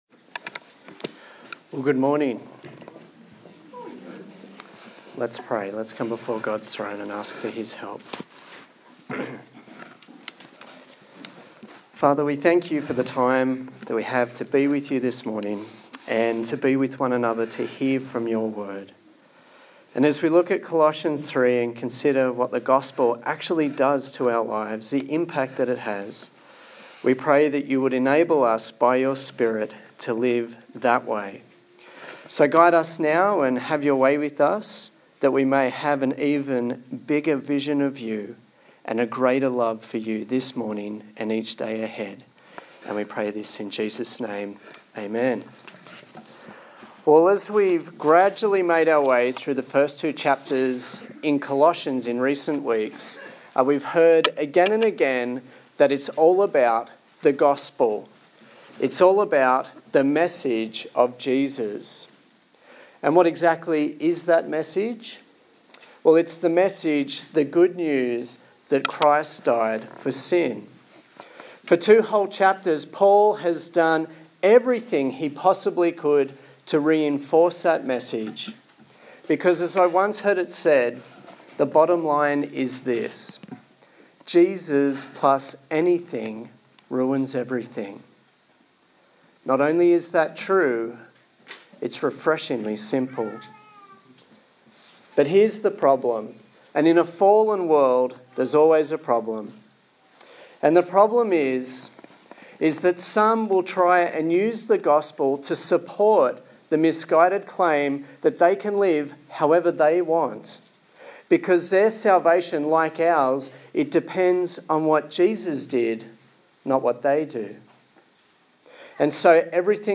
Colossians Passage: Colossians 3:1-11 Service Type: Sunday Morning